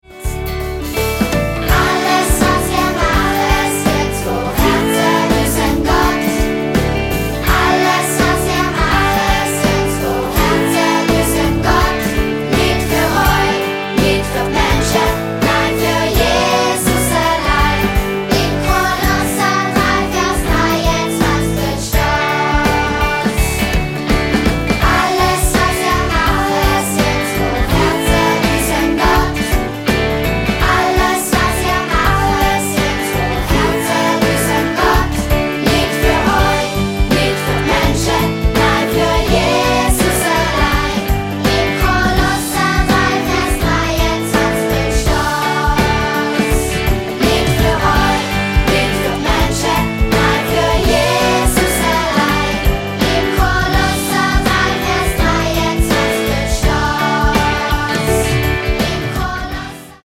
20 Bibelverssongs
24 Bibelverse peppig und eingängig vertont